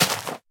grass2.mp3